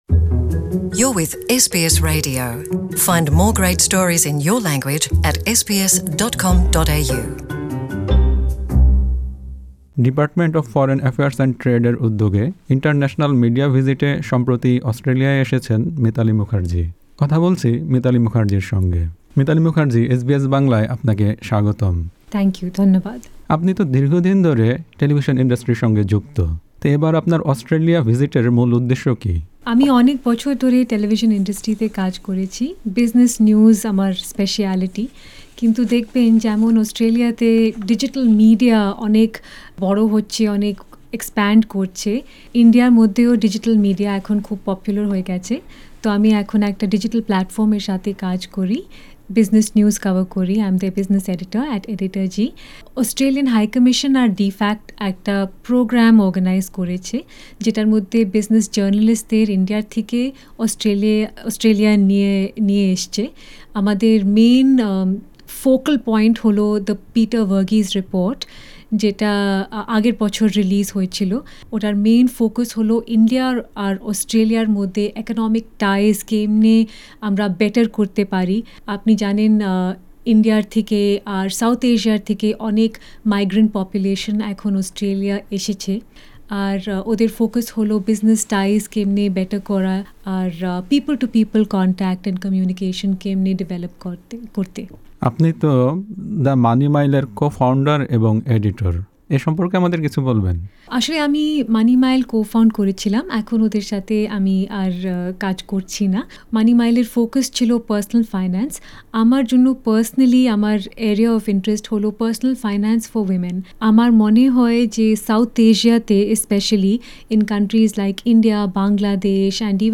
full interview in Bangla in the audio player above